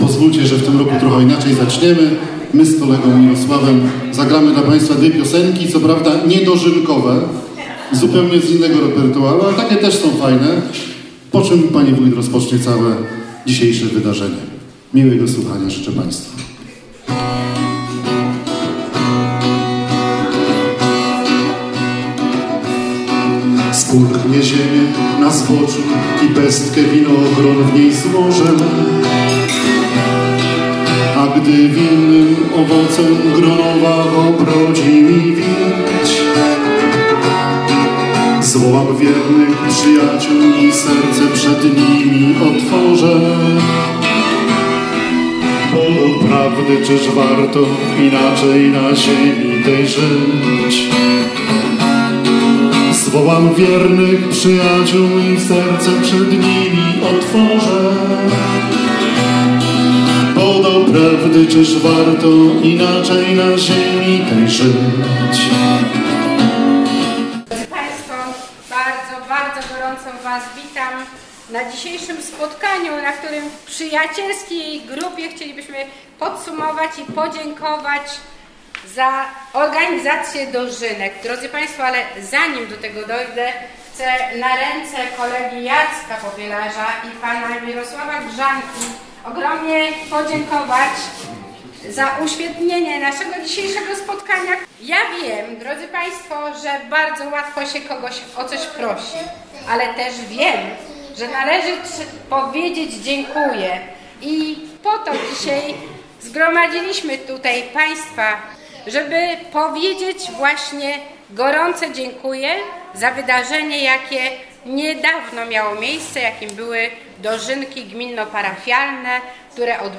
W świetlicy wiejskiej w Rożku odbyło się spotkanie, podczas którego wójt Danuta Mazur podziękowała współorganizatorom tegorocznych Gminno-Parafialnych Dożynek w Brzeźnie.
Podczas spotkania wójt gminy Krzymów podziękowała wszystkim za ich wkład i zaangażowanie w organizację dożynek, które są jednym z najważniejszych wydarzeń w kalendarzu gminnym.